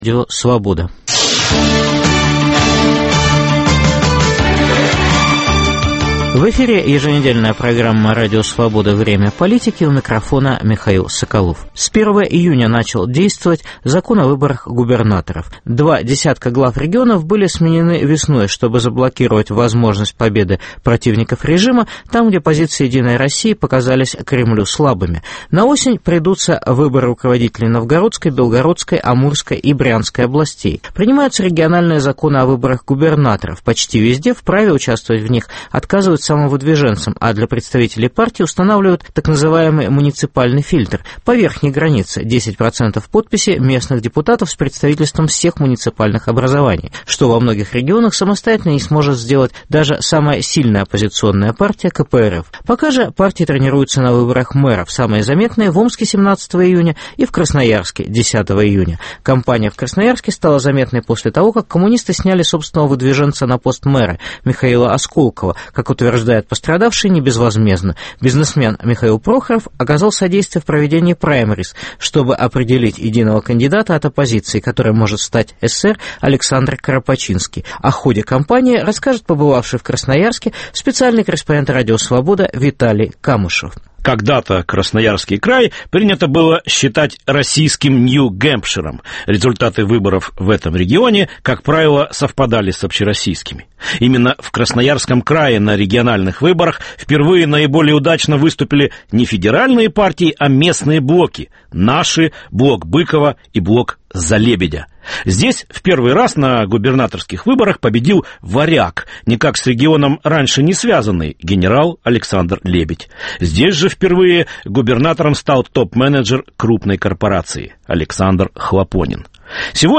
Успешные праймериз оппозиции или олигархическая дискредитация демократов? Выборы мэра Красноярска. Репортаж